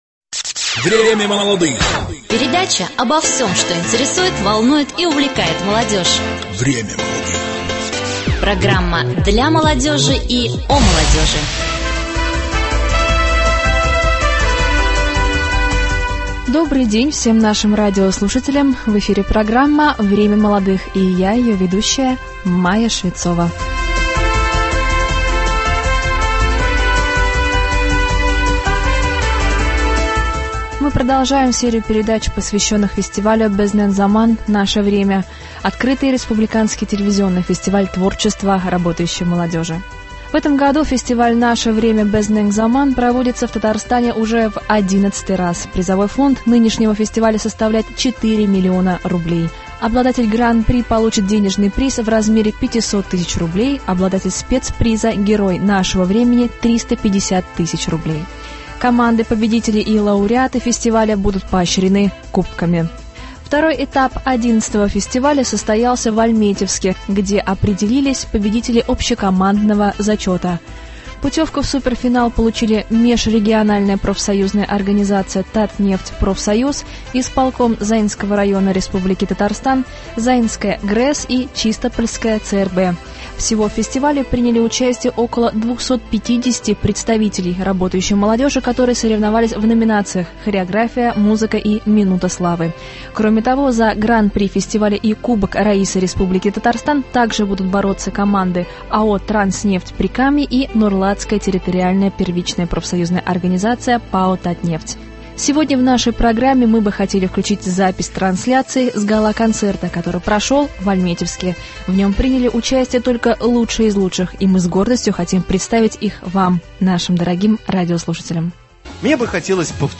Зональные этапы в г. Альметьевске. Гала-концерт.